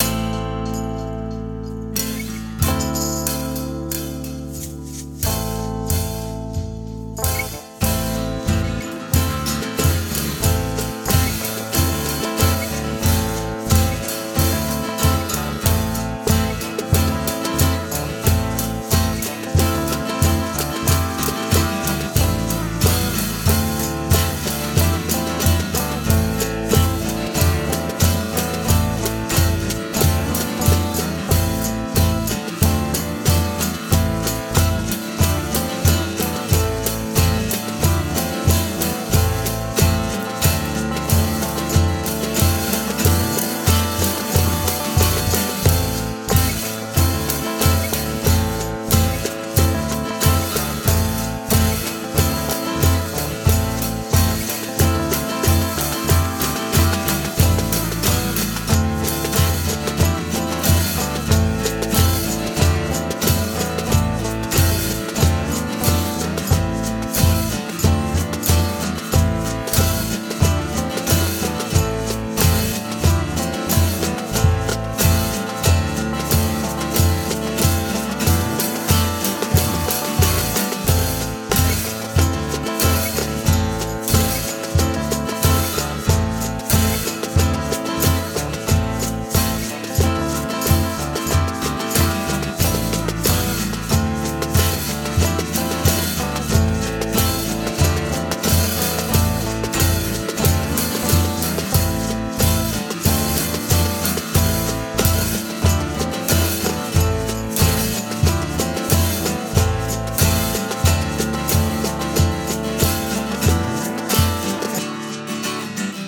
Cajon Information
I have a tune I have tried out, this is just a quick mix. Drum sits in the mix, but there is no MIDI, tambourine and cajon.